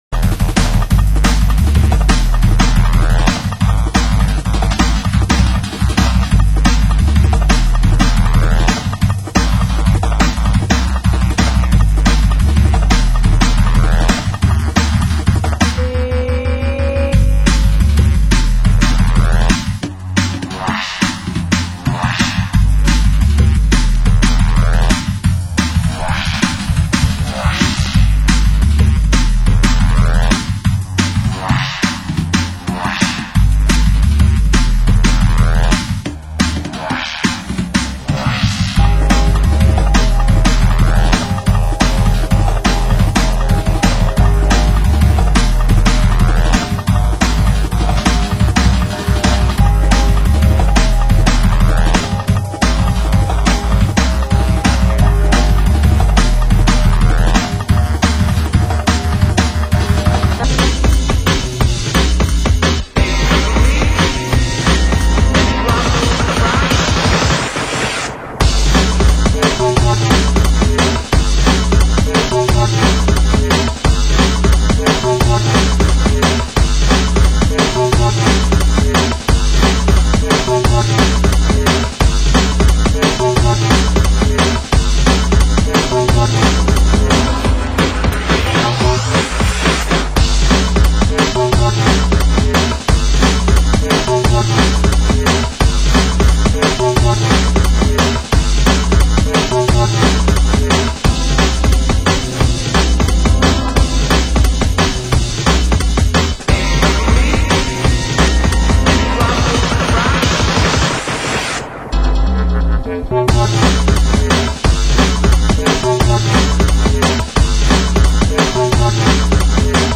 Format: Vinyl Double Album
Genre: Drum & Bass